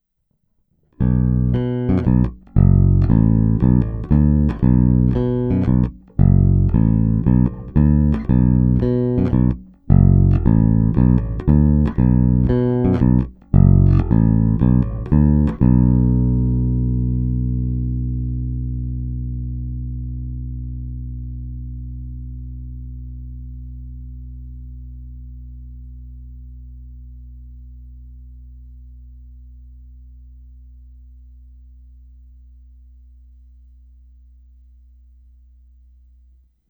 Není-li uvedeno jinak, následující nahrávky jsou provedeny rovnou do zvukové karty a s plně otevřenou tónovou clonou a s korekcemi na nule. Nahrávky jsou jen normalizovány, jinak ponechány bez úprav. Hráno nad použitým snímačem, v případě obou hráno mezi nimi. Na baskytaře jsou nataženy poniklované roundwound pětačtyřicítky Elixir Nanoweb v dobrém stavu.
Snímač u kobylky